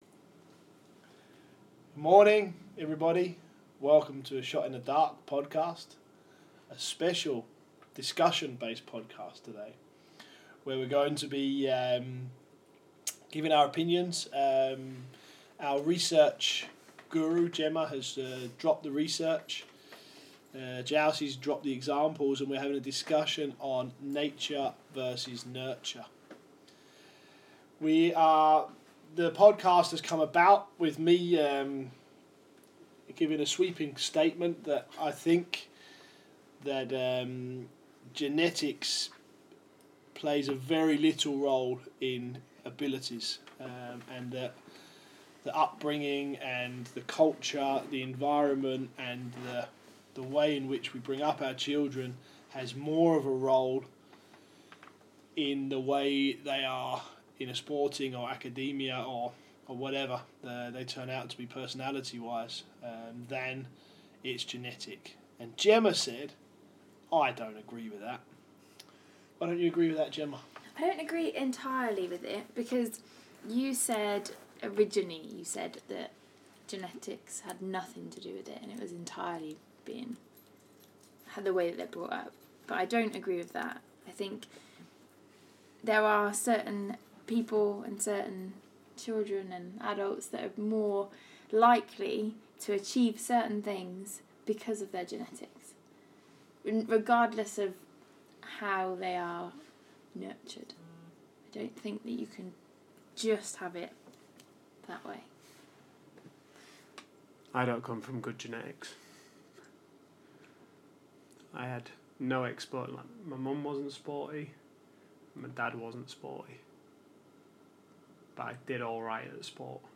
A heavy discussion about how much we as athletes and coaches can influence our performance and how much is genetically driven